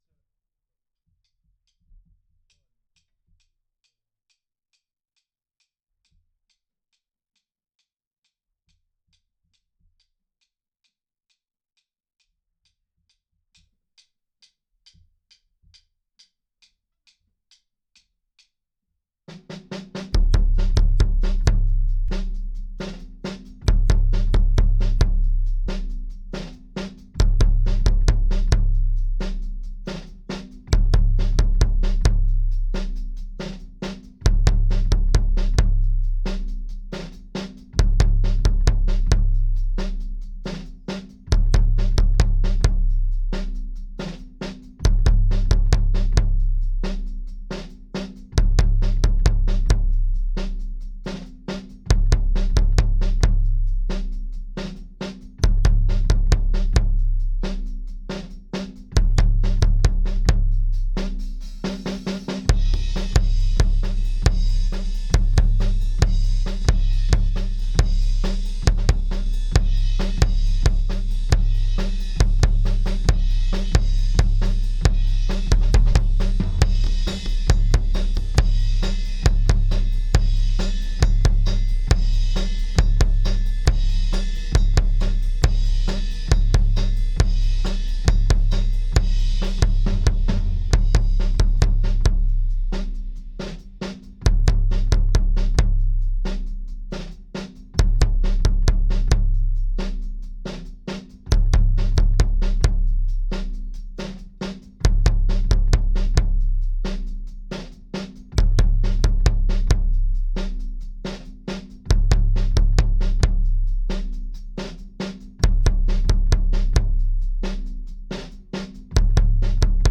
Kick1Compressed.wav